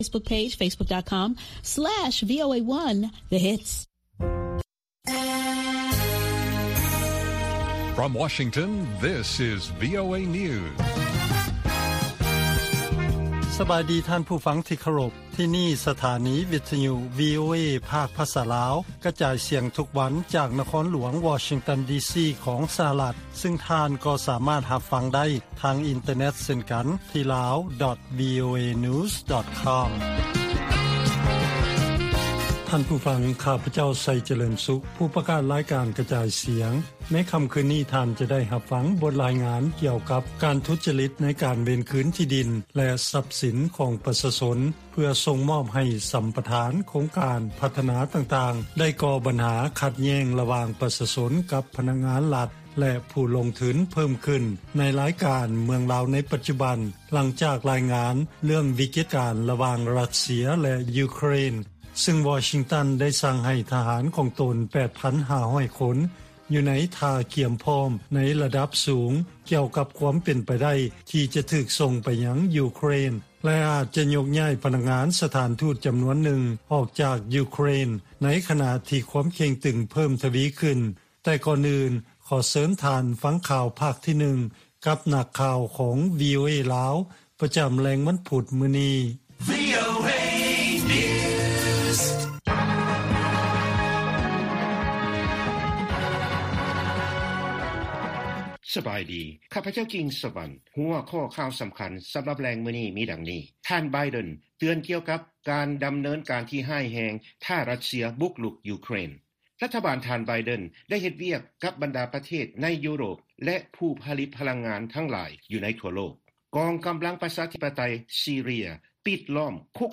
ລາຍການກະຈາຍສຽງຂອງວີໂອເອ ລາວ: ທ່ານ ໄບເດັນ ເຕືອນ ກ່ຽວກັບ ການດຳເນີນການທີ່ຮ້າຍແຮງ ຖ້າ ຣັດເຊຍ ບຸກລຸກ ຢູເຄຣນ